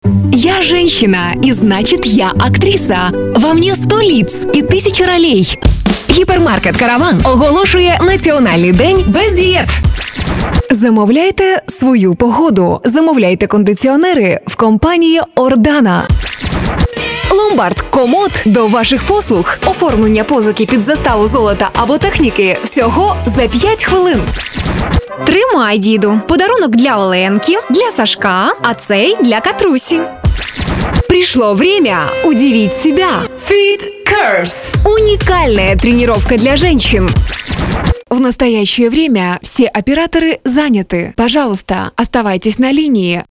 Скачать демо диктора
иностранные Молодой, универсальный, трендовый голос. Возрастной диапазон от 14 до 30 лет. Работаю в кабине звукозаписи, микрофон RODE NT-1, звуковая карта SSL 2+